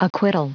Prononciation du mot acquittal en anglais (fichier audio)
Prononciation du mot : acquittal